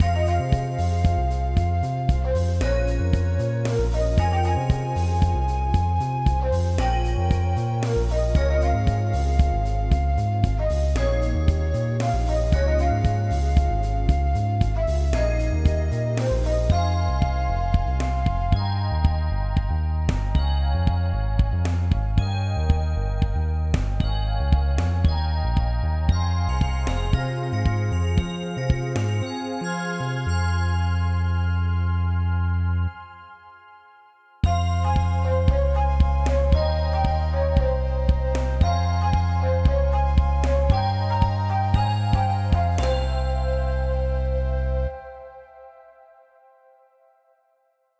A collection of unused music.